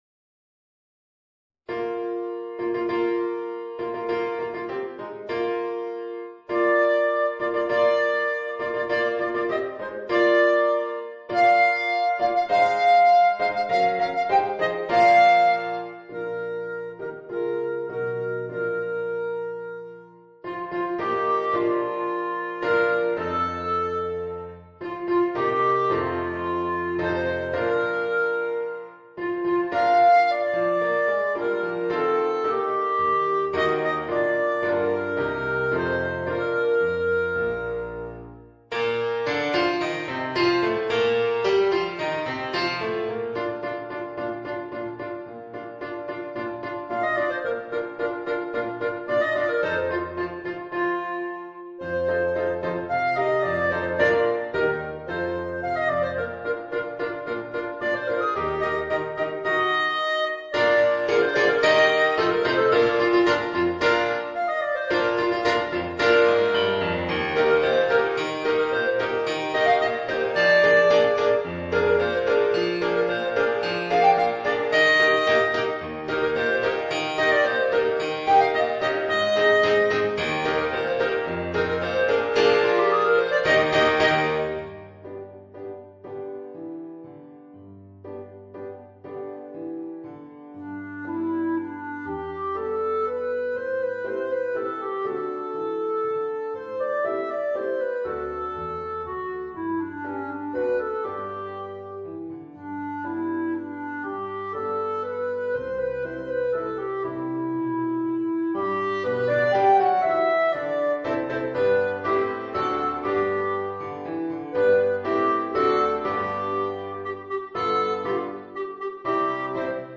Clarinet & Piano